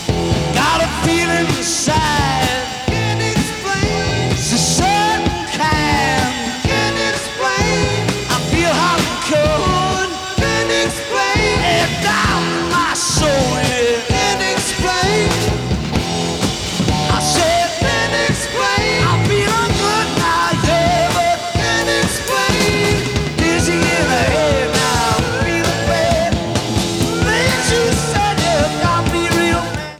Format/Rating/Source: CD - B+ - Soundboard